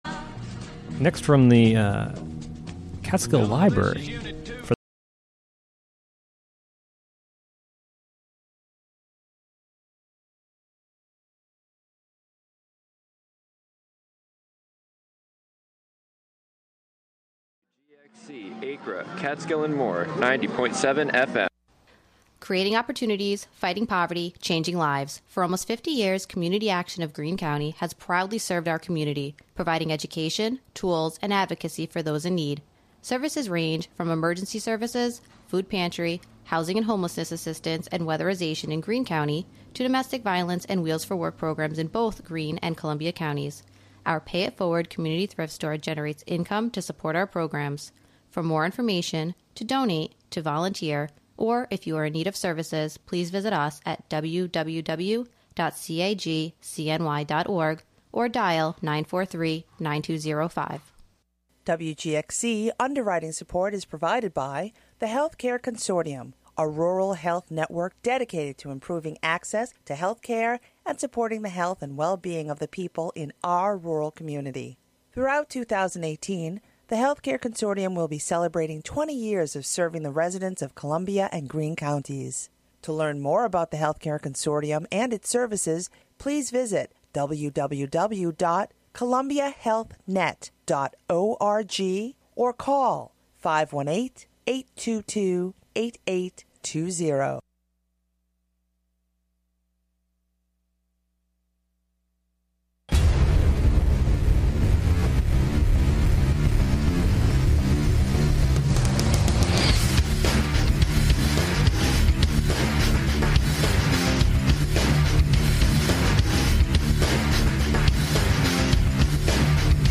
Broadcast live from the Carnegie Room of the Catskill Library.